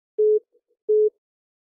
Звук фейстайма входящий видеозвонок по второй линии iPhone